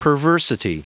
Prononciation du mot perversity en anglais (fichier audio)
Prononciation du mot : perversity